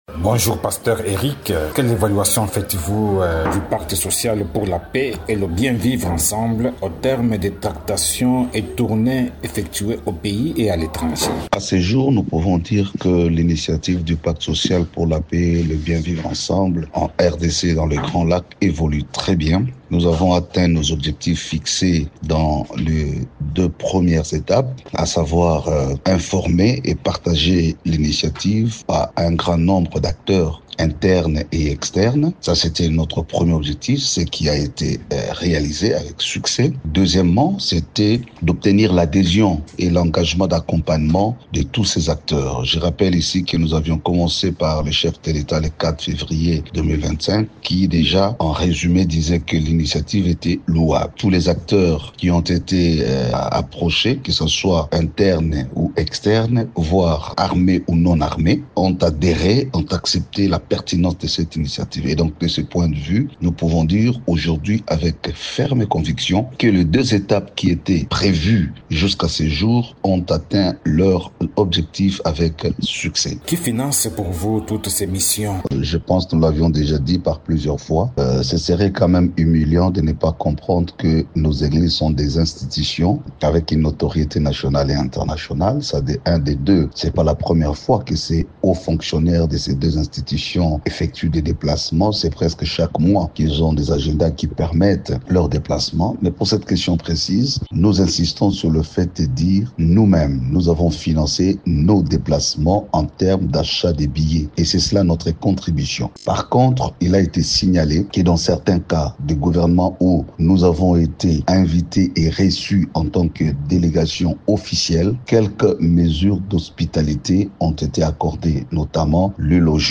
Invité de Radio Okapi, ce révérend se réjouit que ce projet ait été partagé avec un grand nombre d’acteurs internes et externes.